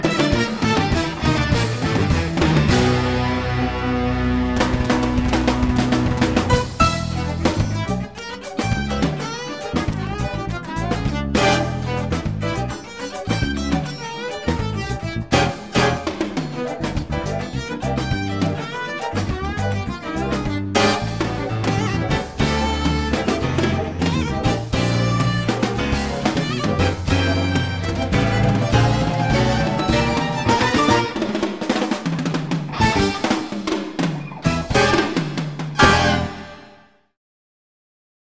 piano and synths
guitar
bass
drums